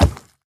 Minecraft Version Minecraft Version 1.21.5 Latest Release | Latest Snapshot 1.21.5 / assets / minecraft / sounds / mob / piglin_brute / step1.ogg Compare With Compare With Latest Release | Latest Snapshot